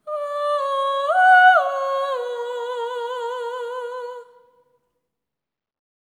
ETHEREAL11-R.wav